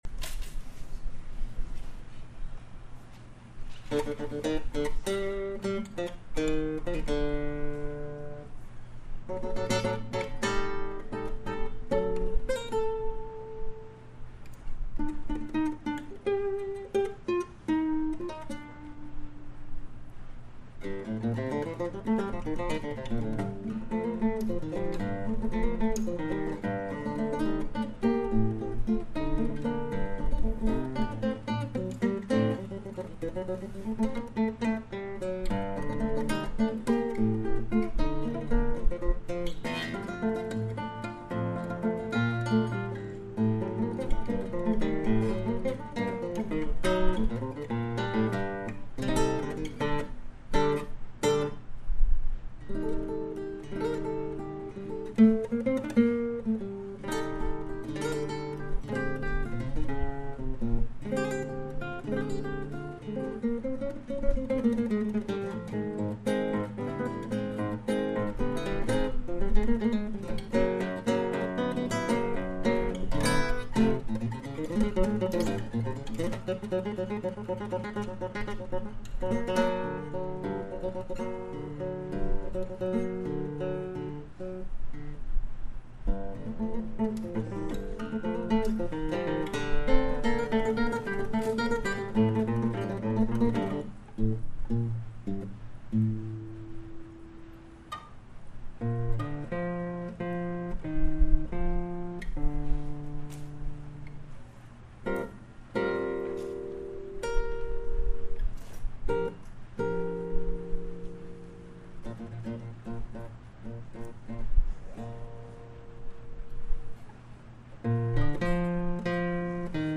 ABA Guitar piece played in class.